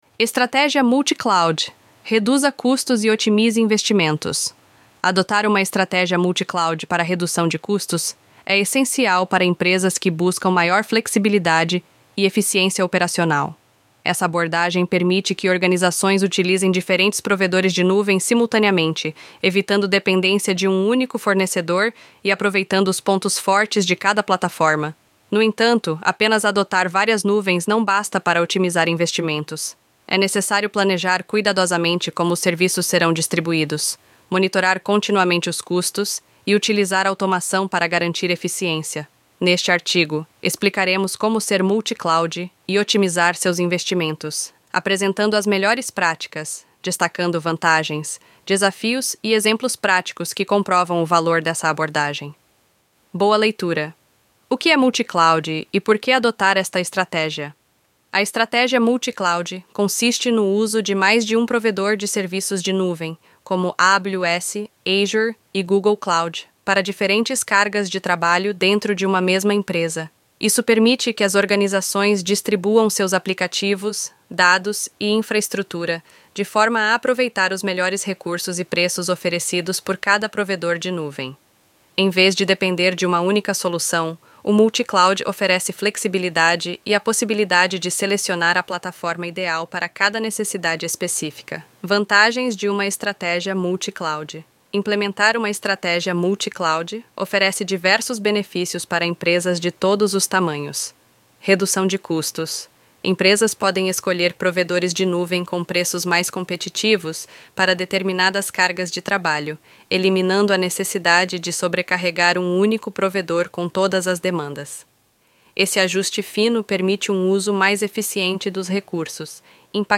Texto-sobre-Estrategia-Multicloud-Narracao-Avatar-Rachel-ElevenLabs.mp3